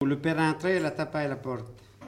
Enquête Arexcpo en Vendée-Lucus
Catégorie Locution